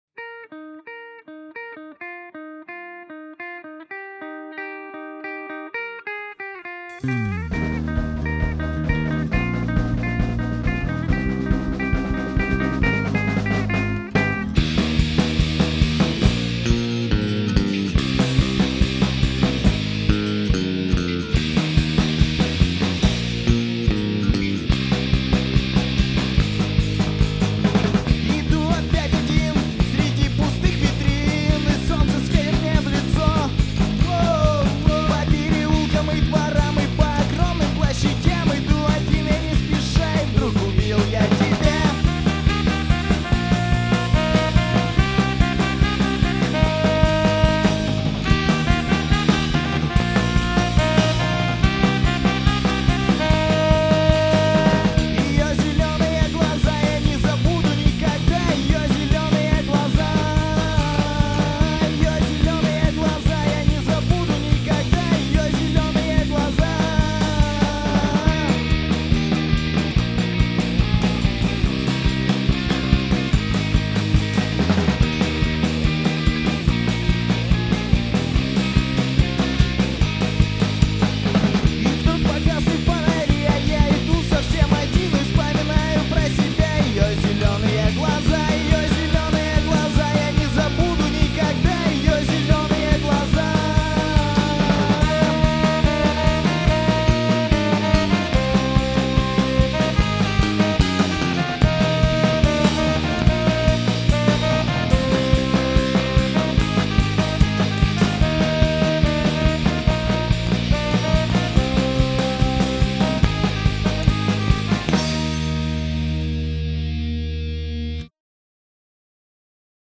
поп-панк